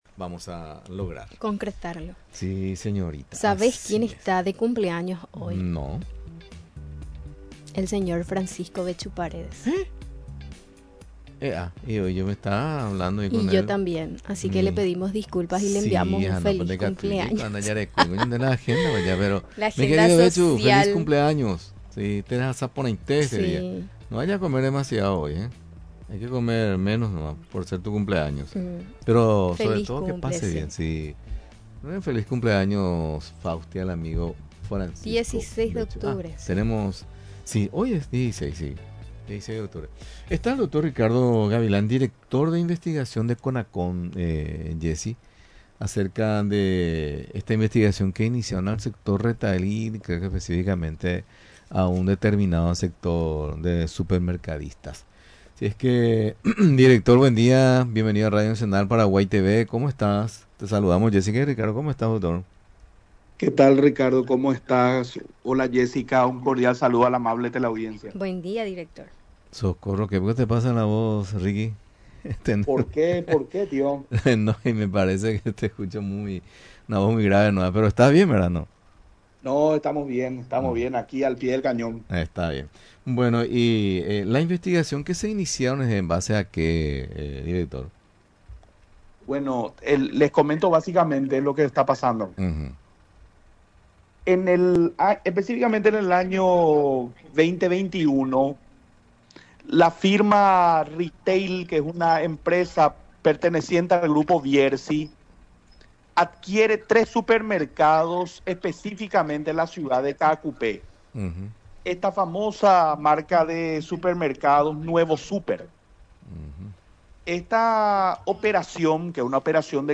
Durante la entrevista en Radio Nacional del Paraguay, explicó que ésta situación se dio cuando la autoridad aprobó la compra de tres locales de la cadena Nuevo Súper, en la ciudad de Caacupé.